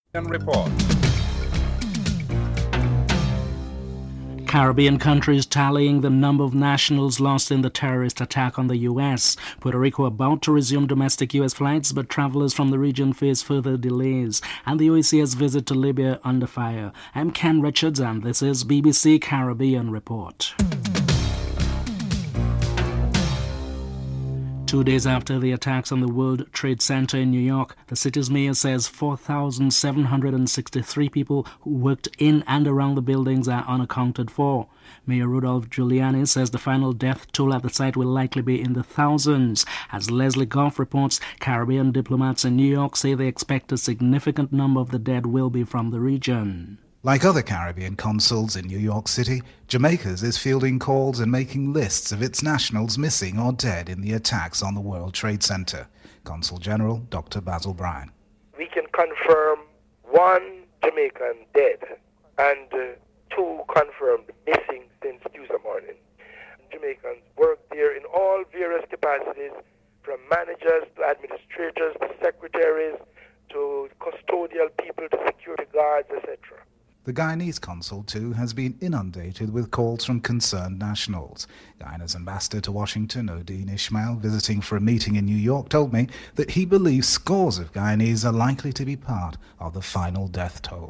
1. Headlines (00:00-00:25)
Sir John Compton is interviewed (14:01-15:21)